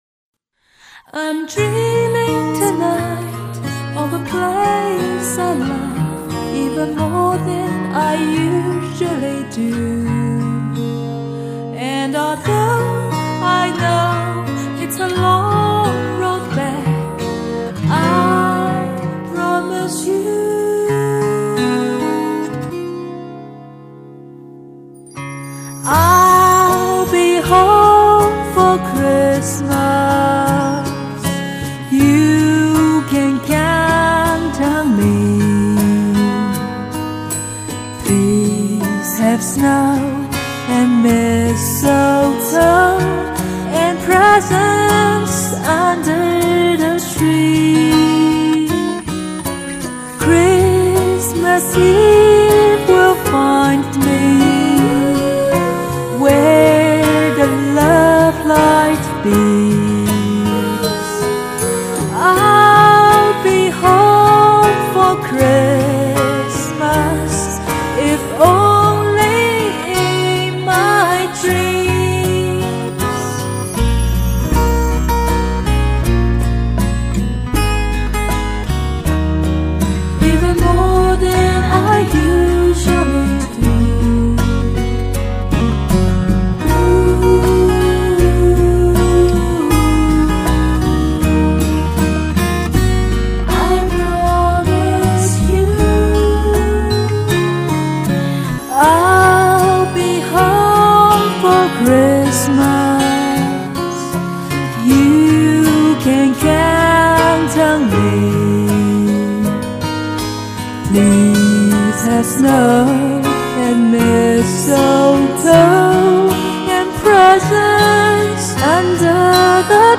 07年圣诞节，第一次录英文歌。可惜没有伴奏，用的消音。